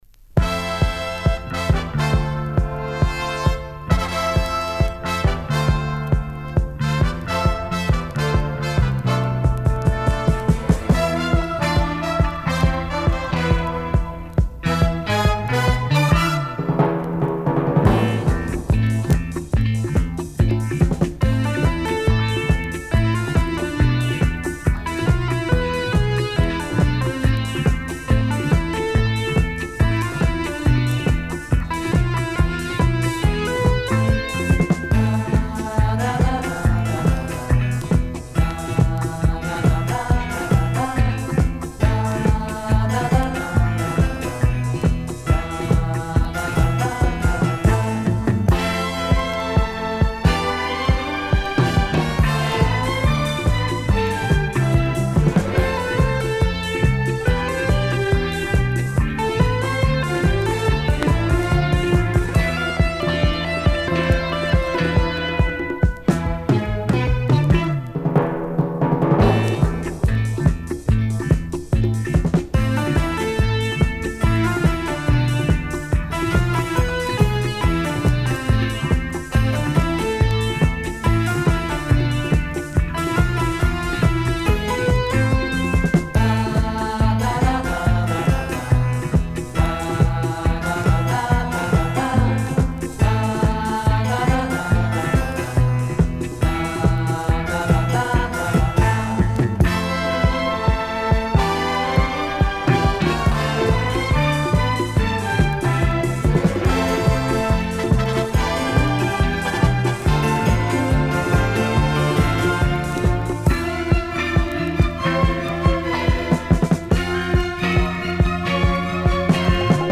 Замечательный оркестр!